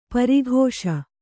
♪ pari ghōṣa